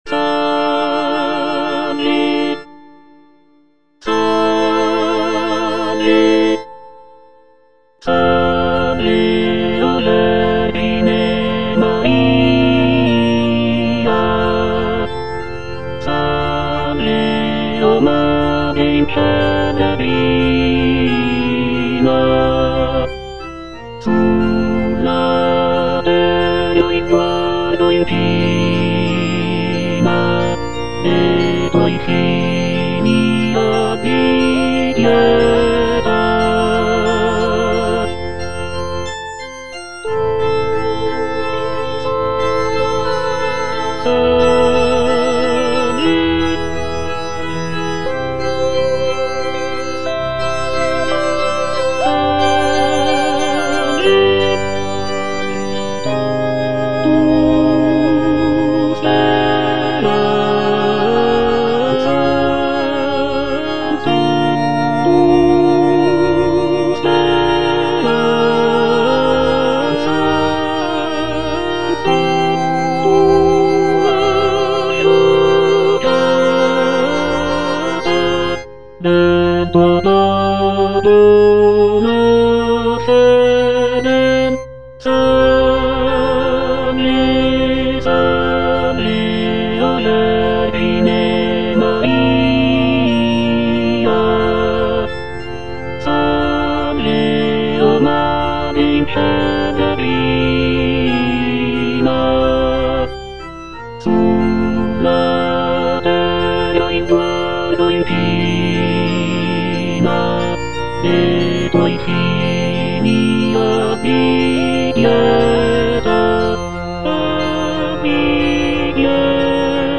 Tenor (Emphasised voice and other voices)
choral piece